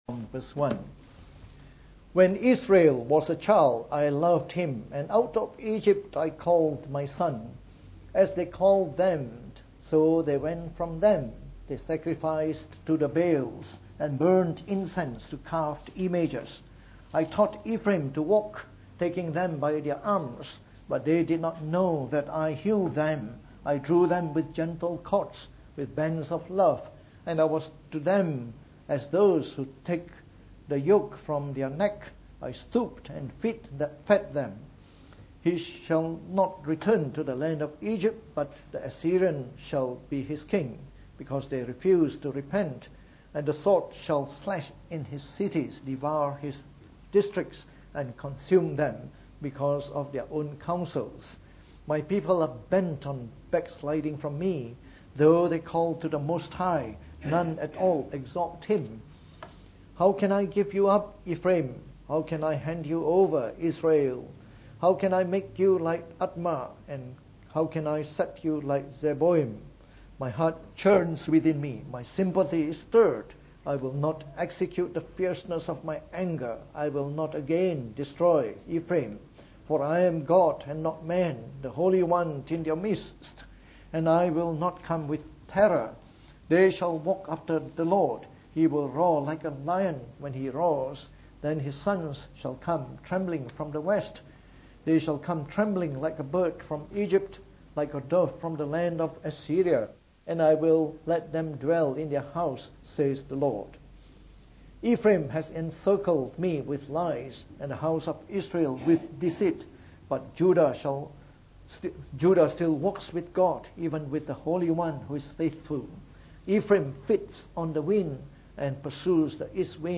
From our series on the Book of Hosea delivered in the Morning Service.